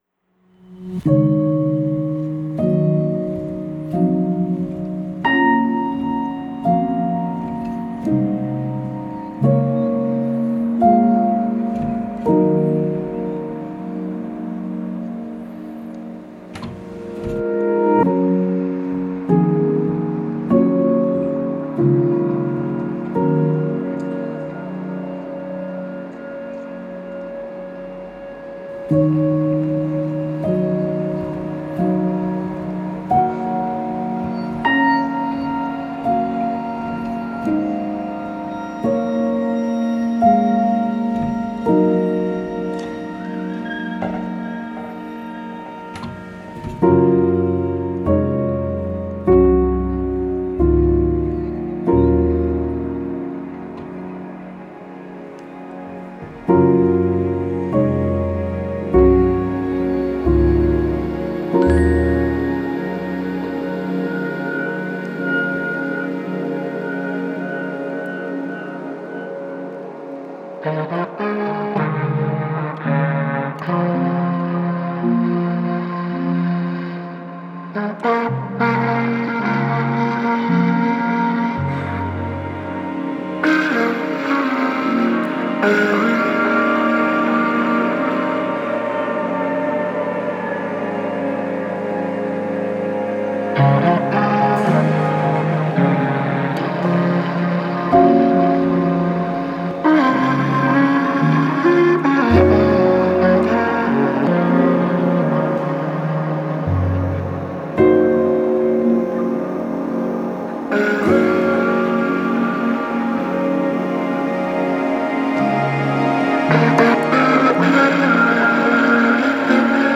2. The clarinet.
Tags2010s 2011 ambient Europe-Asia